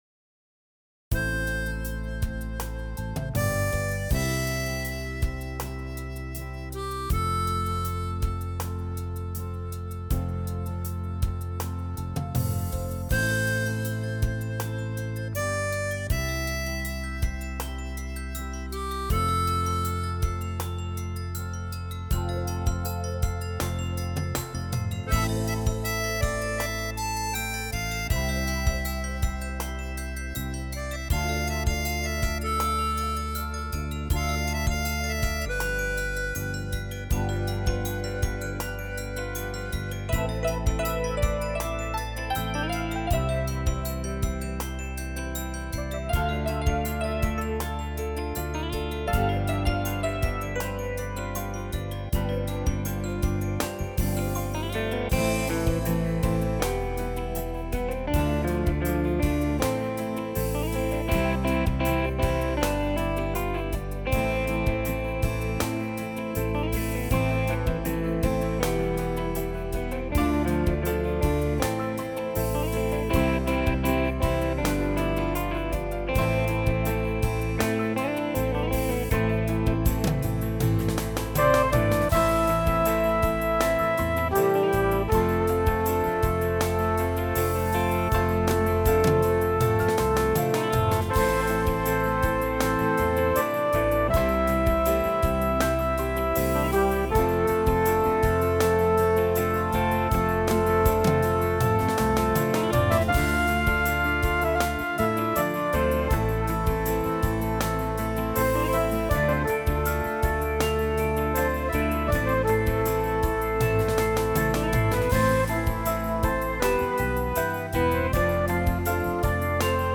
קטע נגינה קצר של שיר ישראלי ישן – בסגנון בוסה נובה
קטע בוסונובה.mp3